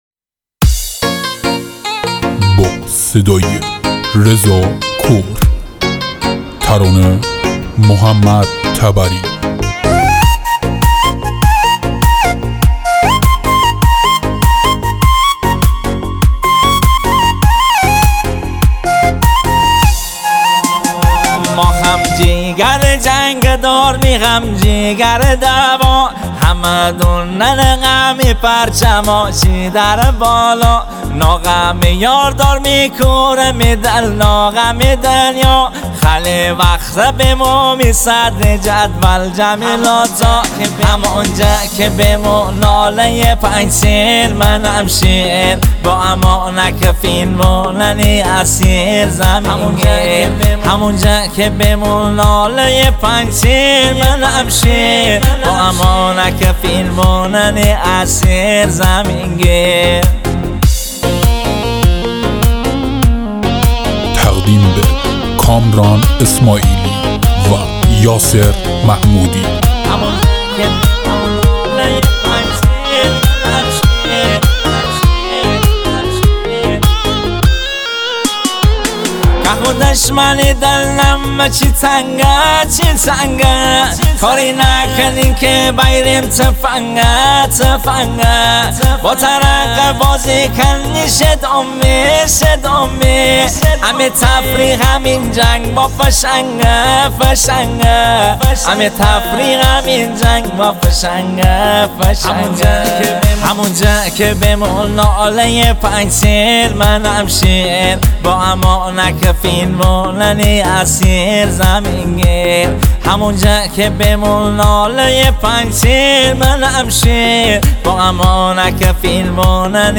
دانلود آهنگ لاتی مازندرانی غمگین و شاد